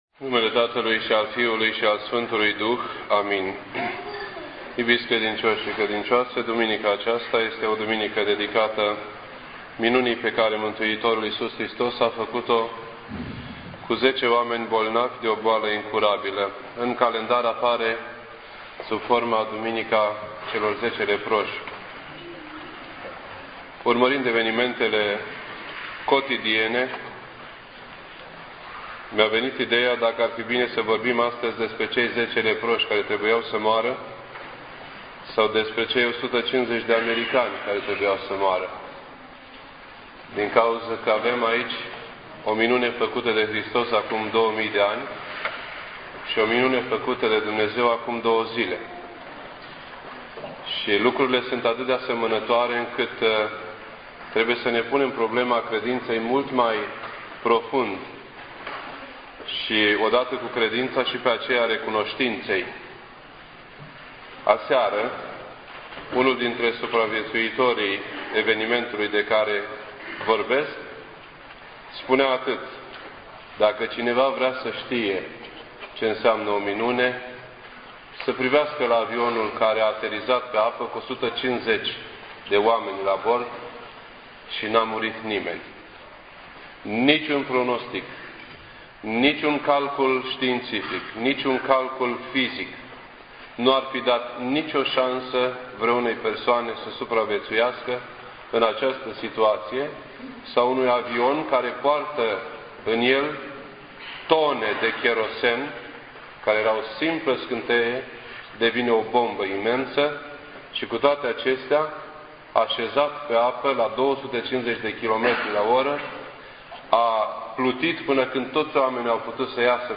This entry was posted on Sunday, January 18th, 2009 at 6:58 PM and is filed under Predici ortodoxe in format audio.